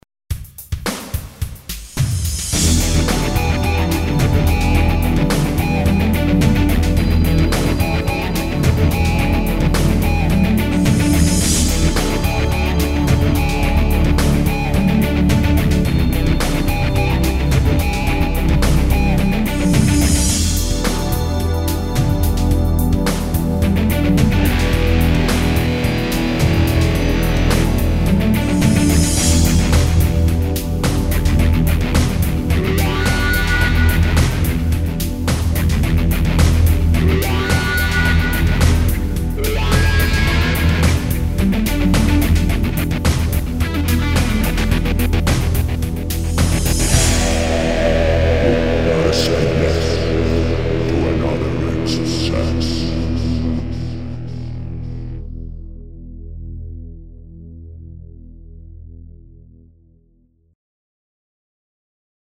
Scene #10 - Palm muting, bending + wah.